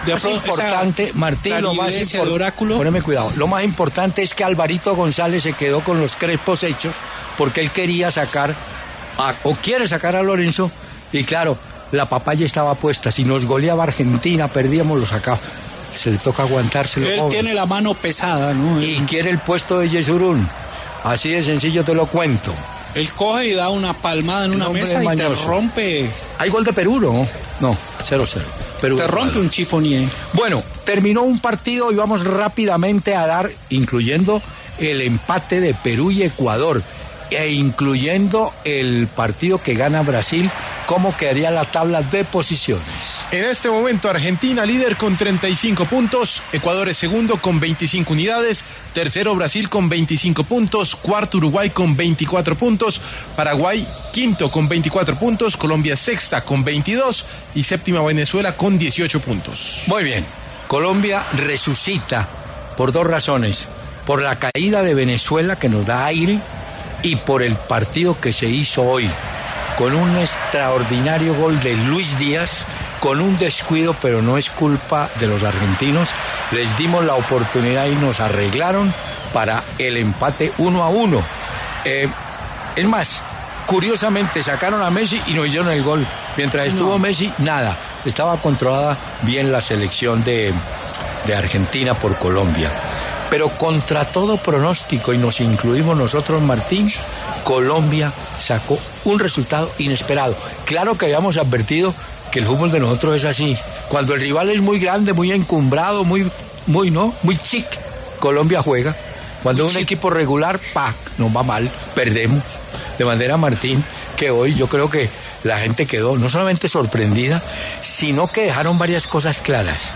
Hernán Peláez, periodista deportivo de W Radio, entregó su análisis luego del empate 1-1 de la Selección Colombia con Argentina en el estadio Monumental de Buenos Aries, señalando que la Tricolor “resucito” en la Eliminatoria luego de este resultado como visitante.